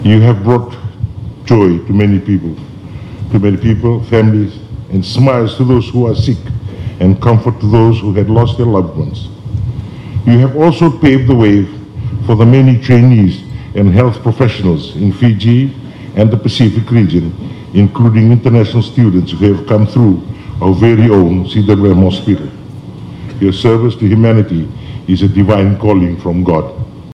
Ratu Wiliame expressed this sentiment during the CWMH’s 100th-anniversary reception at the State House last night.
In his address, the President warmly acknowledged both past and present staff of the hospital for their unwavering service to the people.